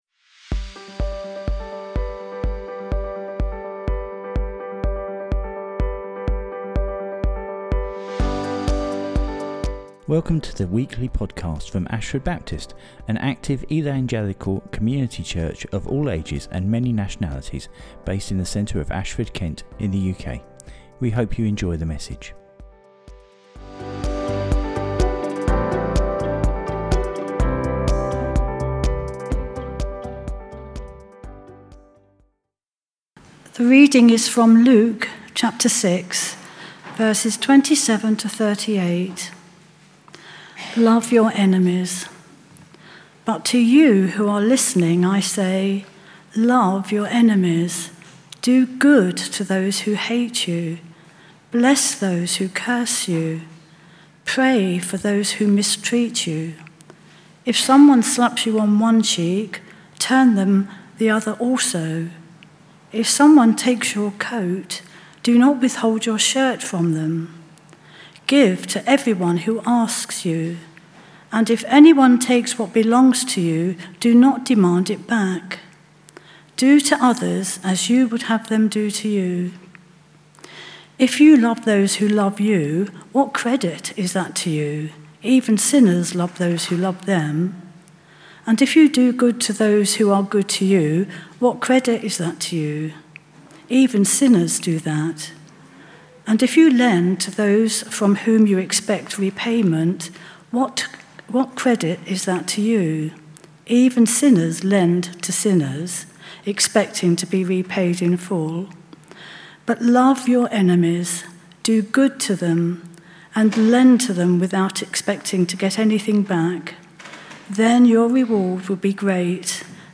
The bible readings are from Luke 6.